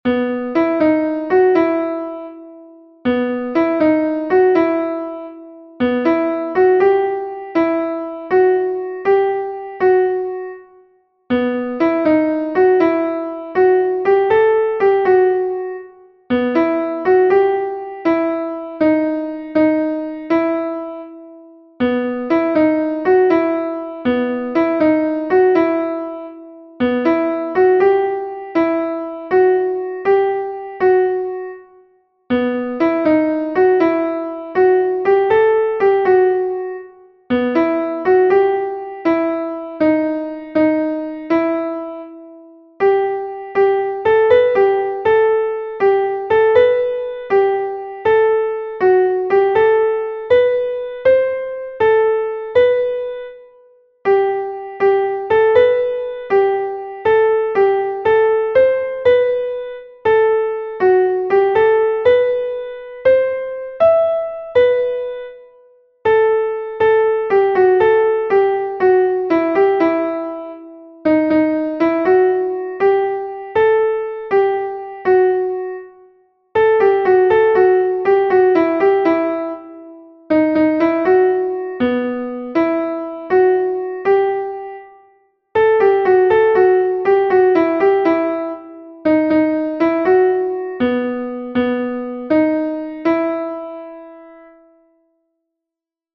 Sentimenduzkoa
Hamaseiko handia (hg) / Zortzi puntuko handia (ip)
AA1AA1BB1CC1